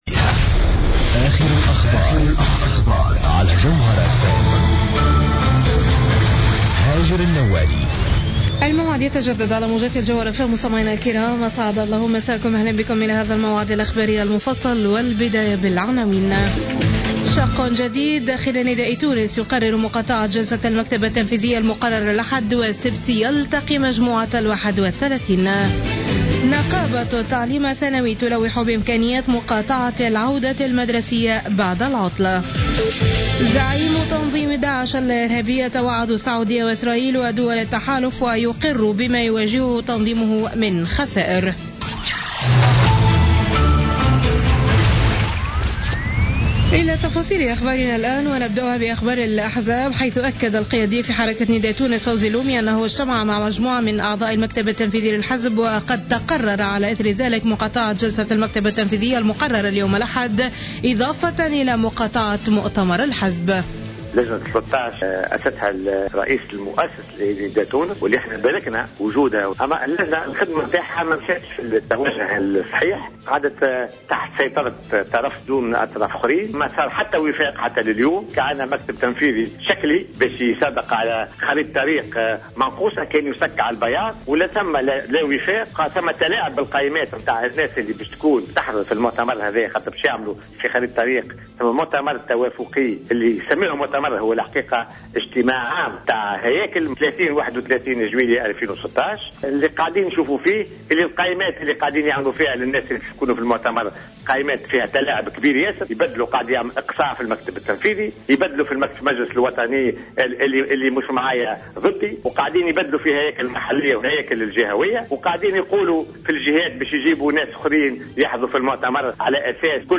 نشرة أخبار منتصف الليل ليوم الأحد 27 ديسمبر 2015